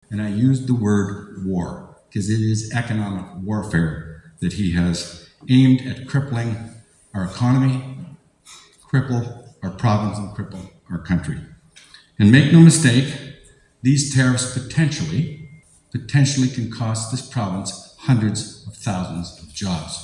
Four candidates appeared at a forum last Tuesday, hosted by the St. Thomas & District Chamber of Commerce.